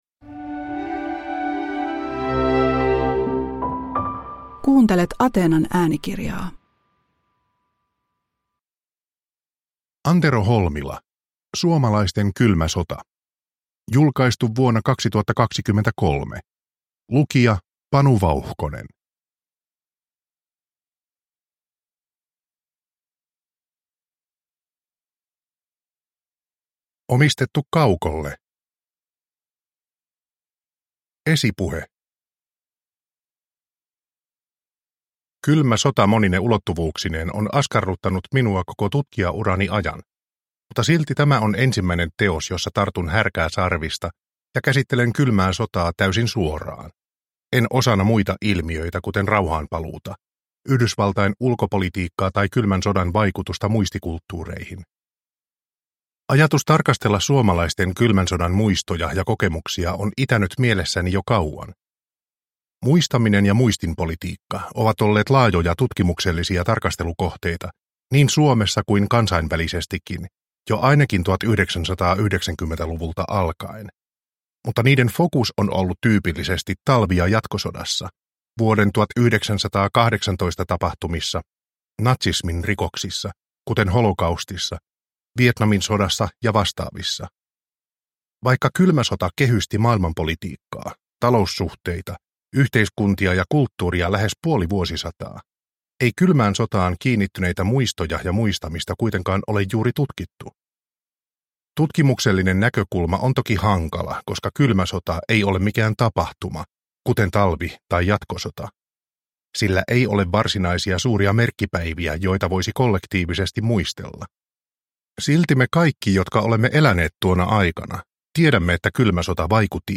Suomalaisten kylmä sota – Ljudbok